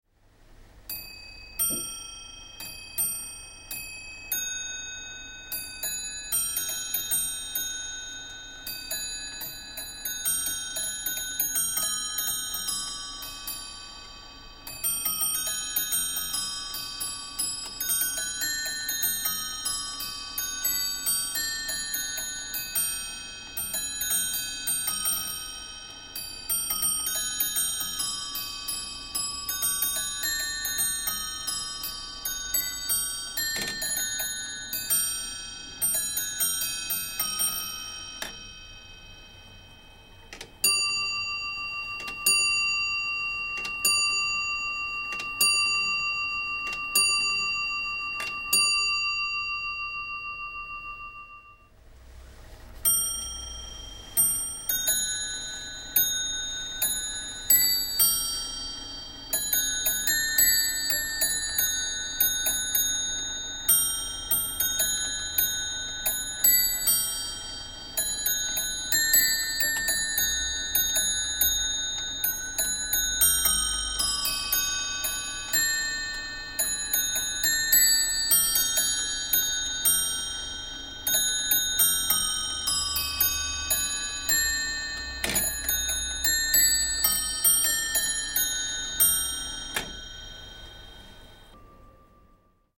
Title English Musical clock with automata.
A fine musical clock with moving figures in the arch, signed on a silvered plaque above the bottom winding hole Jn Parkes London, circa 1770.
The three train gut wound fusee movement with verge escapement, plays(very well) one of two tunes at each hour on eight bells with fifteen hammers, followed by the hour strike.